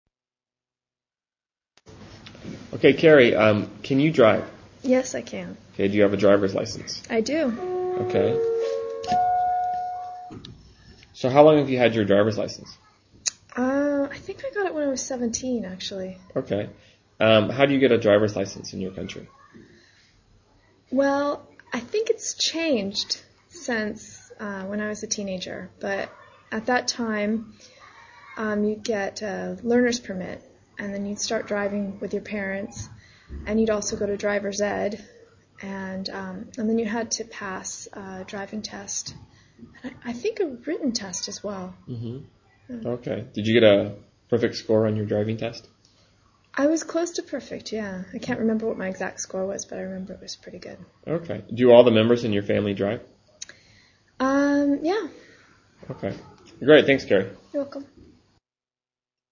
英语高级口语对话正常语速12:驾车（MP3）